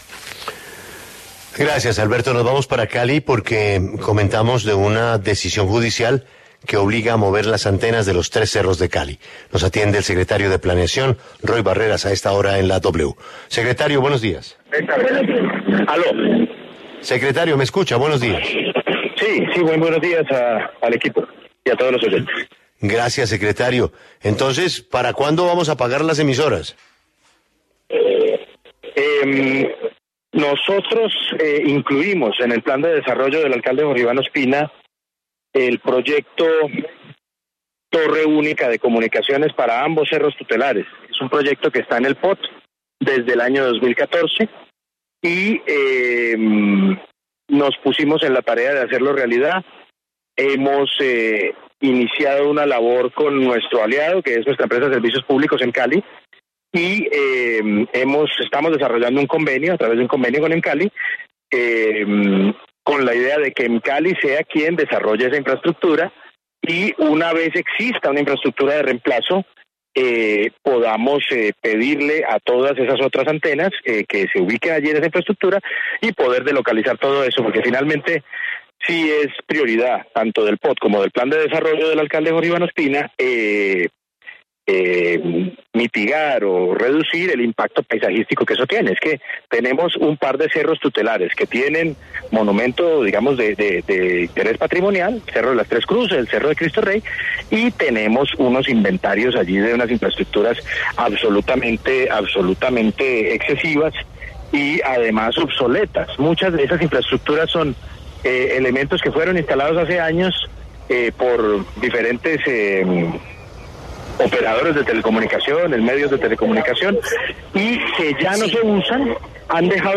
Roy Barreras, director del Departamento Administrativo de Planeación Distrital de la Alcaldía de Cali, explicó en La W cómo se realizará el proceso para mover las antenas en los tres cerros de la ciudad.